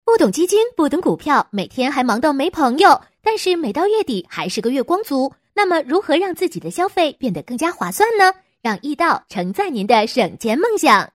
【飞碟说】MG女8-诙谐幽默
【飞碟说】MG女8-诙谐幽默.mp3